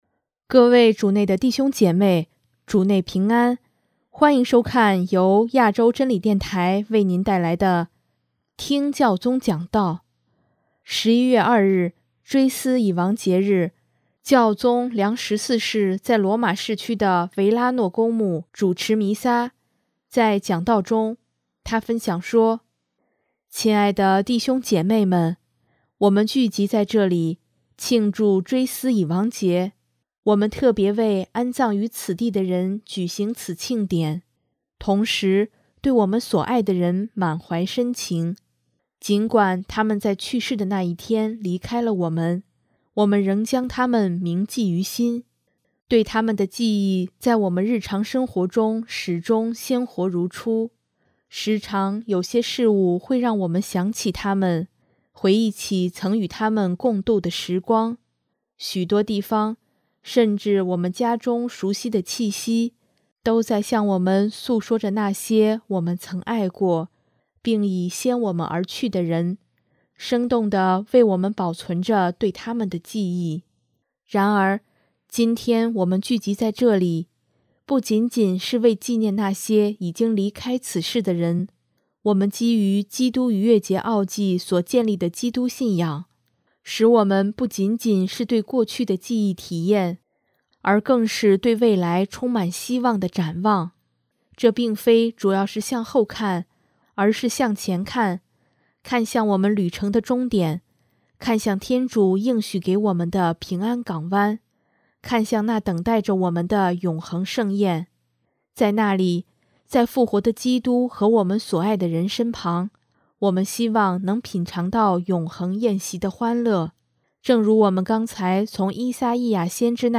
【听教宗讲道】|在爱中的生命本身就是一种上升到天主前的祈祷
11月2日，追思已亡节日，教宗良十四世在罗马市区的维拉诺（Verano）公墓主持弥撒，在讲道中，他分享说：